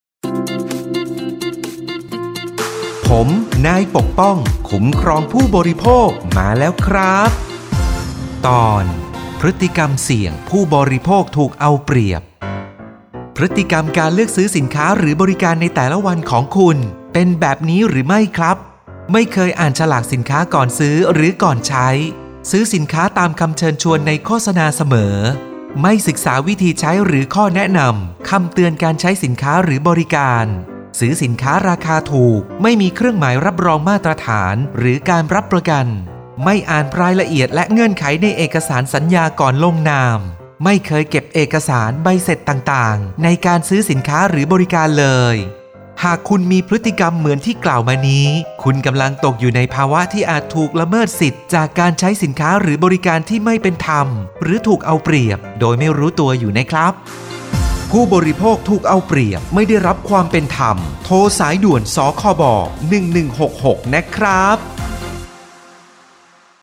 สื่อประชาสัมพันธ์ MP3สปอตวิทยุ ภาคกลาง
026.สปอตวิทยุ สคบ._ภาคกลาง_เรื่องที่ 26_.mp3